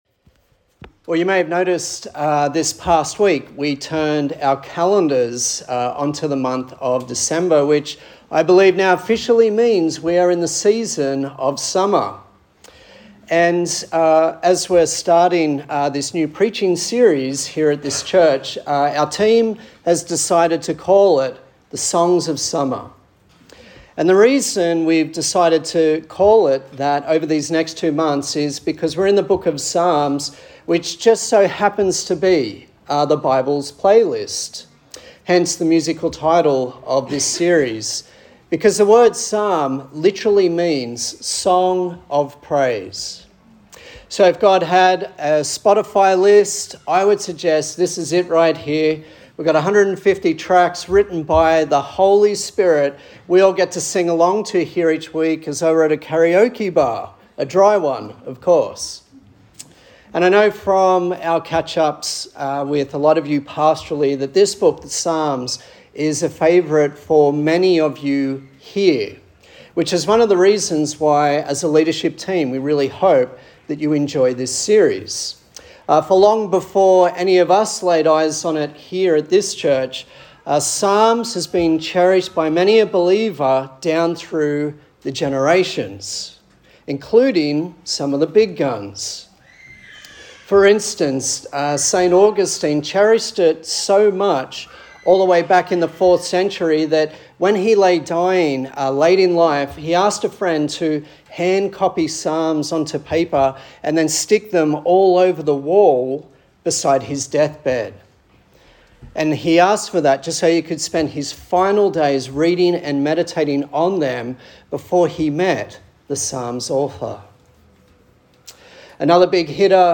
A sermon in the series on 'Songs for Summer' featuring the book of Psalms.